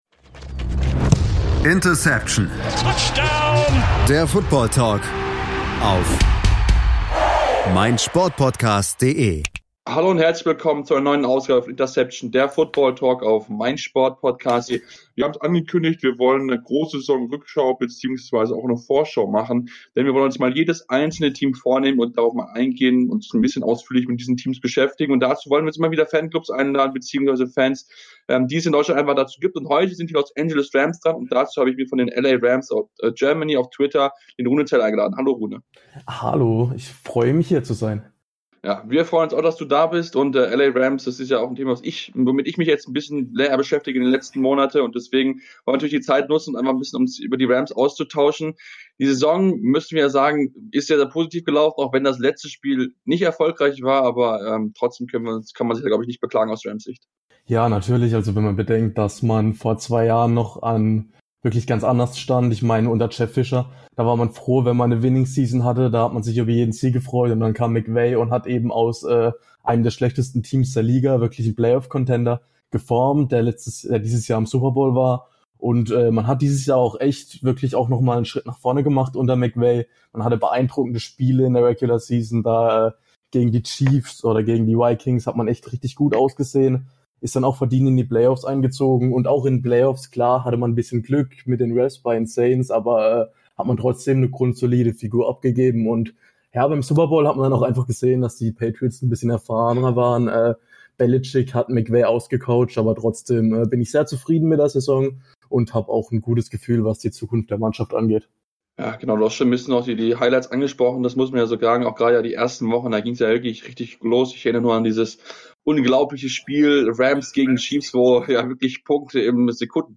Es gibt eine Ausgabe zu jedem Team in der NFL, wo unsere Crew mit Fan-Experten über die jeweiligen Teams sprechen.